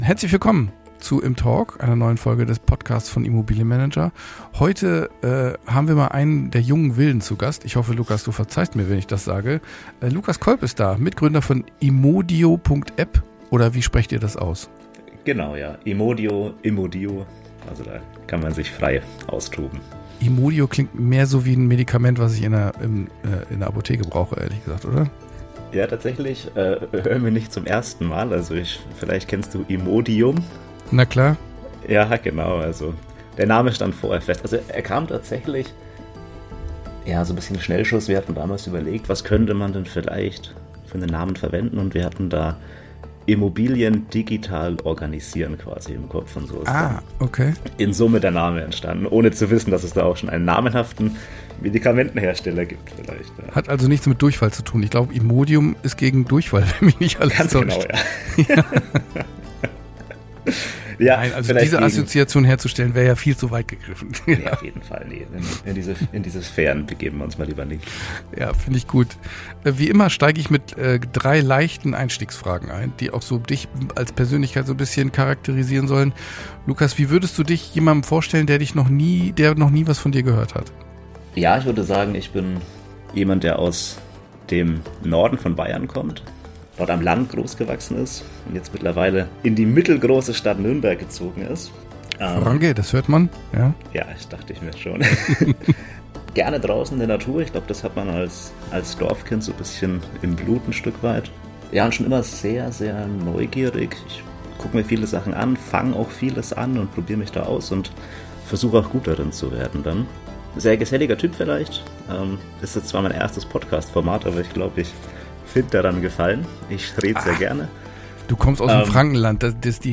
Dabei wird klar, wie viel Frust, manuelle Arbeit und Papierchaos im Status quo stecken und wie Immodio genau hier ansetzt. Im Gespräch geht es außerdem um die Herausforderungen im PropTech-Markt, die Realität hinter „Nebenbei-Gründen“, den Wert von Fokus und warum Unternehmertum oft kein plötzlicher Entschluss, sondern ein schleichender Prozess ist.